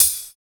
110 HAT 2.wav